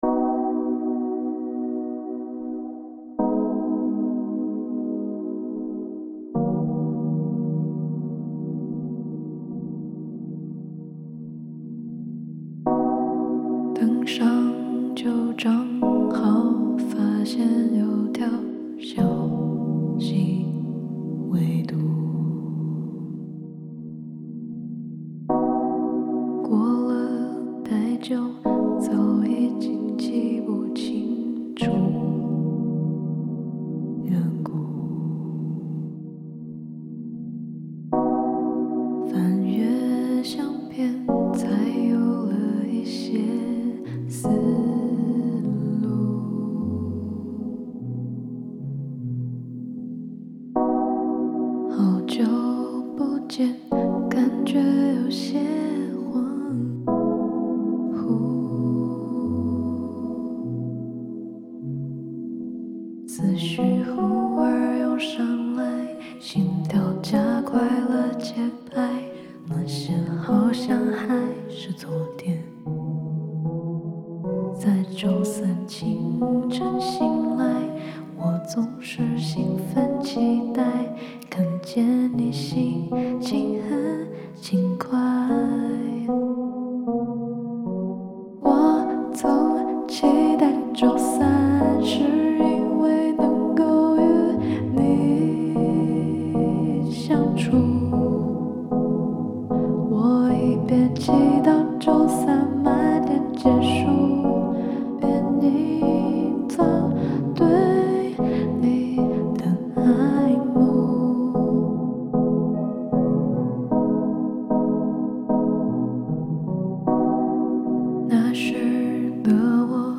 Genre: C-Pop
Version: Demo